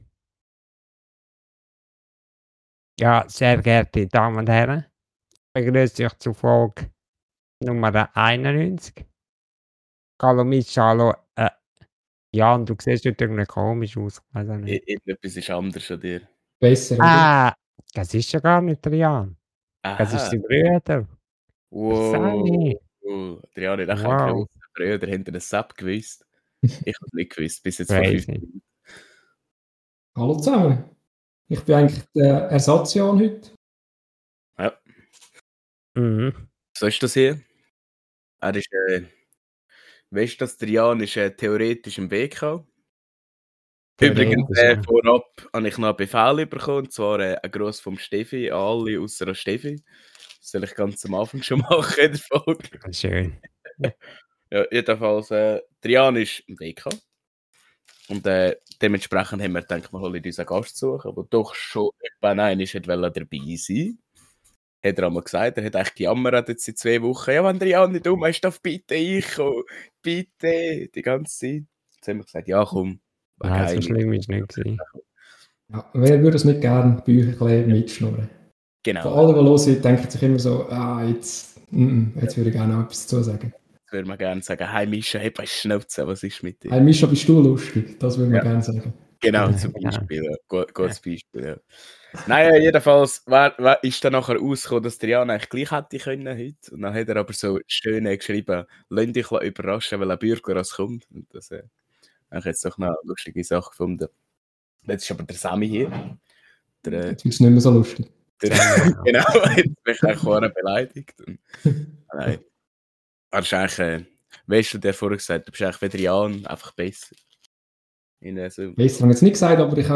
quatschen mit einem Special Guest